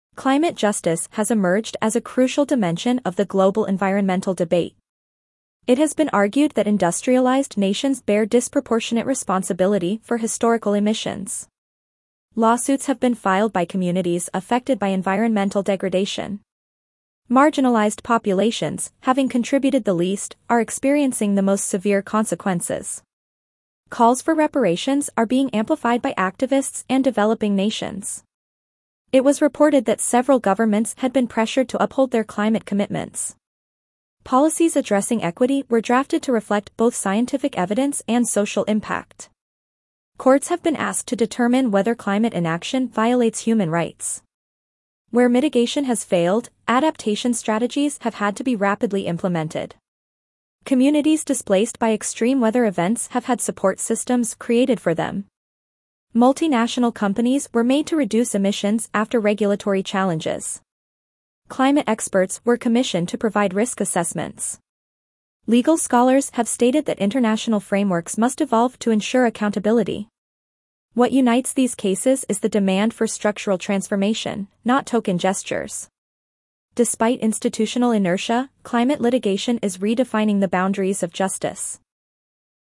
C2 Dictation - Climate Justice